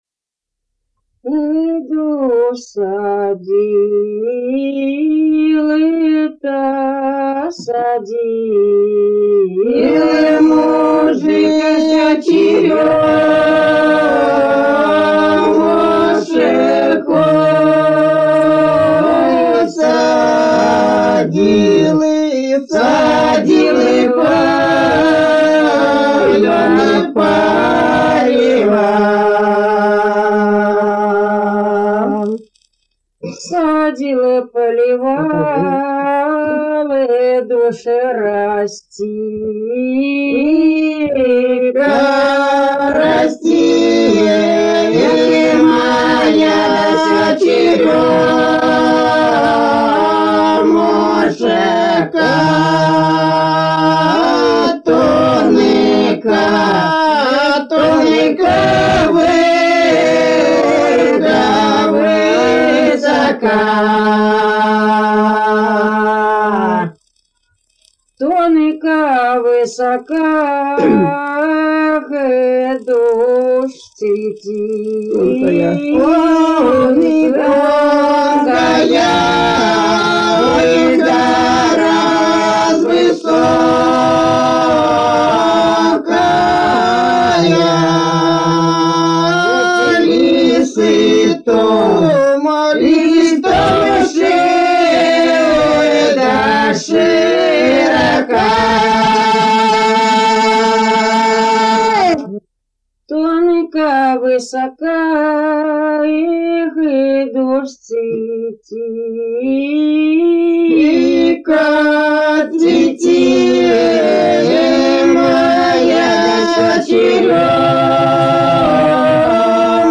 лирическая на Троицу